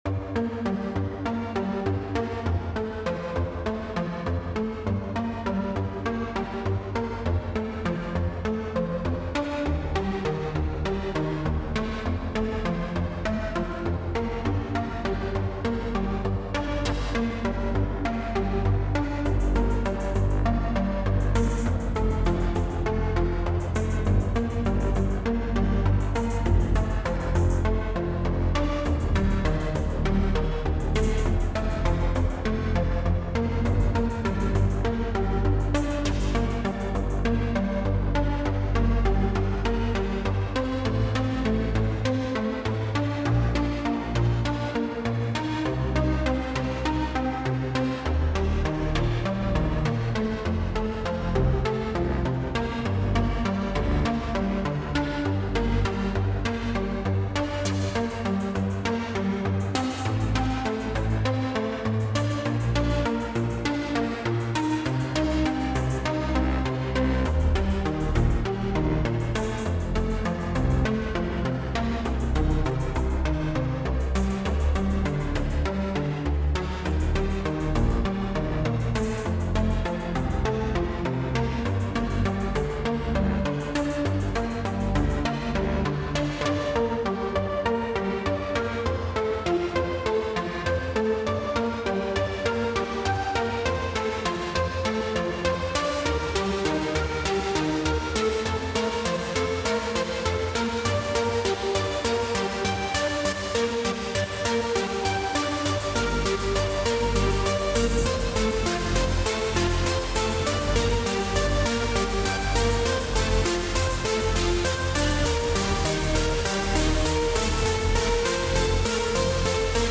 Deep Cocoon, 2025, Sound work (electronics), 2 min 29 sec
A stripped-down experimental piece where I investigated the feeling of falling into one's inner depths.